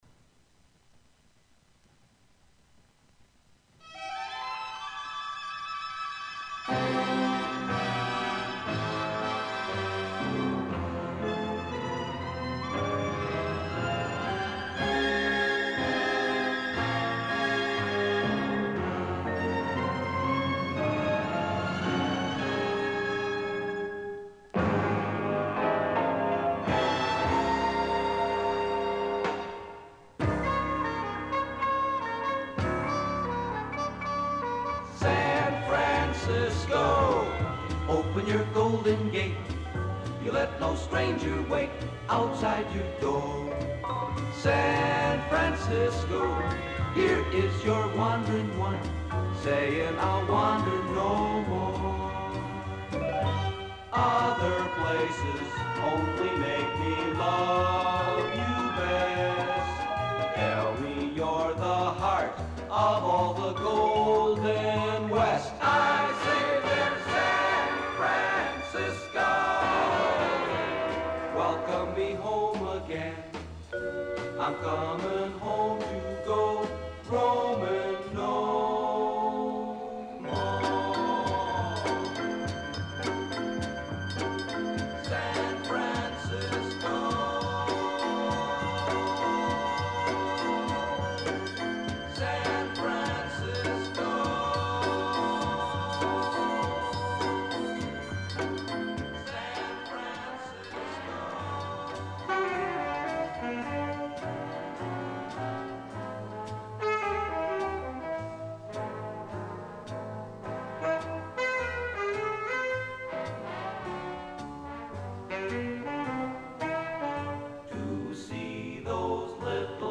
Through the medium of multitrack recording
(60 seperate instruments and voices)